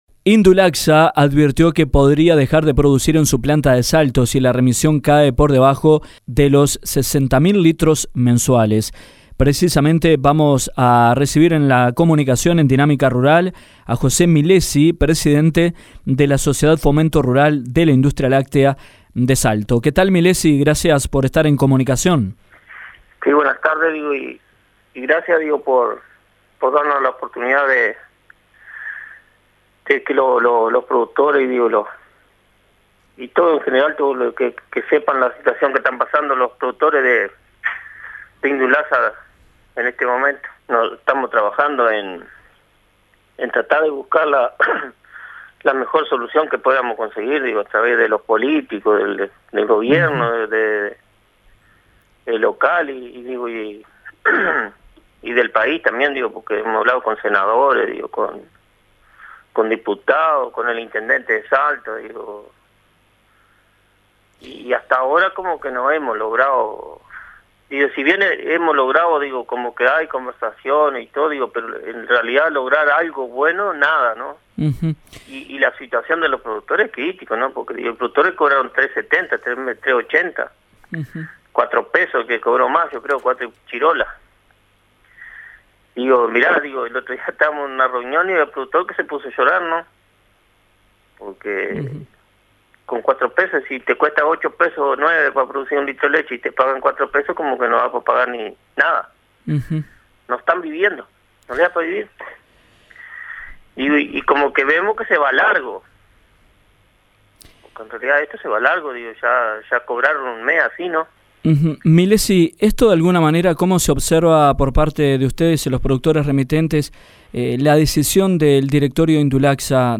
en Diálogo con Dinámica Rural